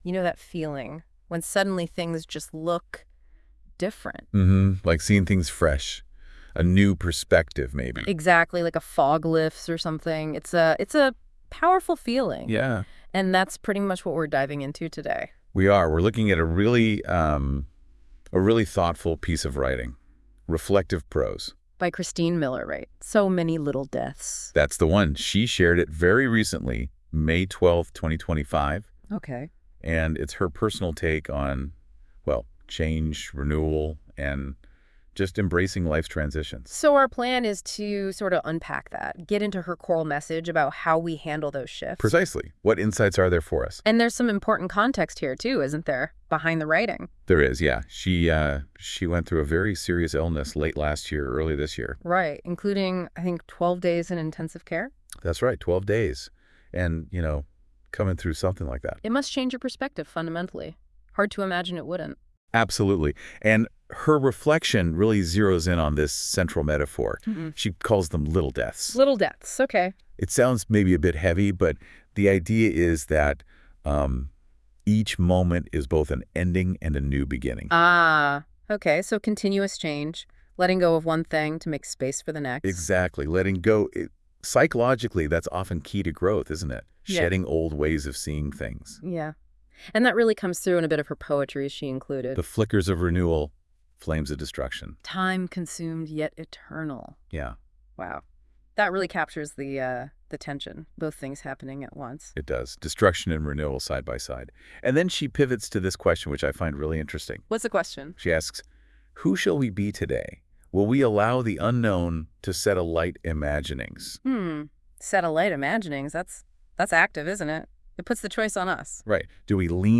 2025 Podcast Discussion https